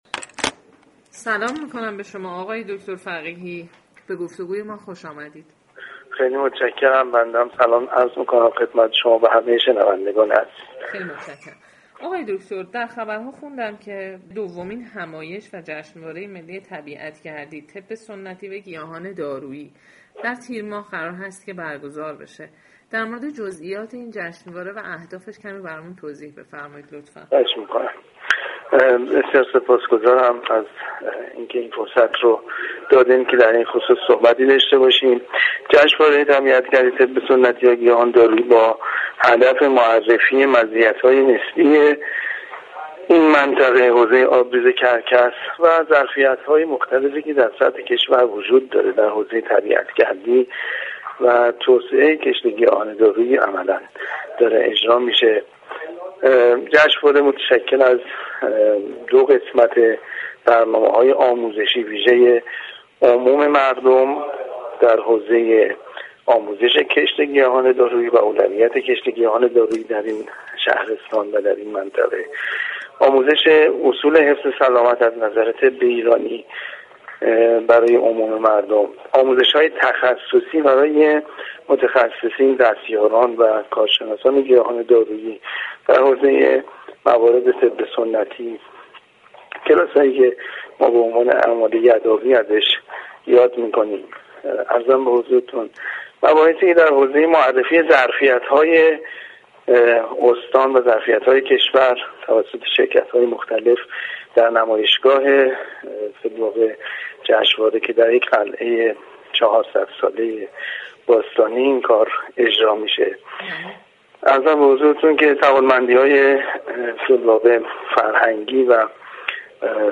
گفتگوی اختصاصی با سایت رادیو فرهنگ